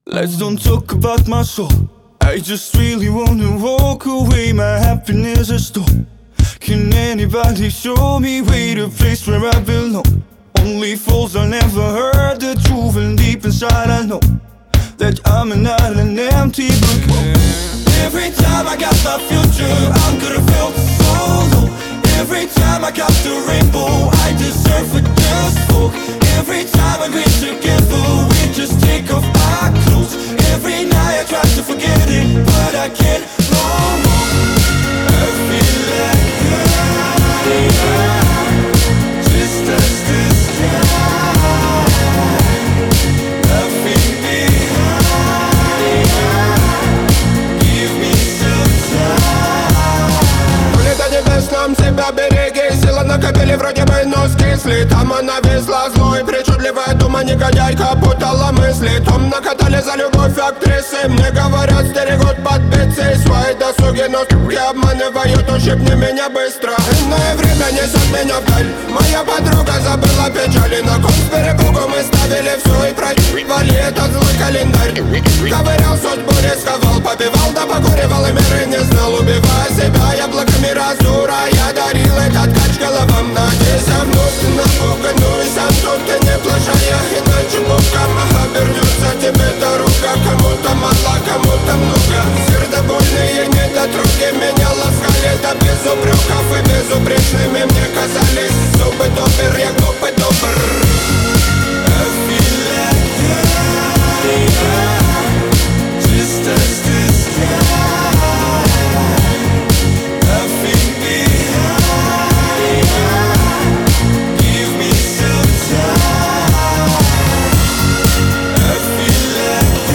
Трек размещён в разделе Русские песни / Танцевальная / 2022.